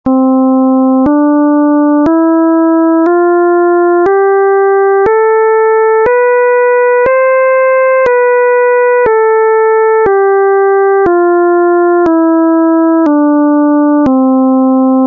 Οἱ ἤχοι ἔχουν παραχθεῖ μὲ ὑπολογιστὴ μὲ ὑπέρθεση ἀρμονικῶν.]
Διατονική Διδύμου
diatonic_didymos_262.mp3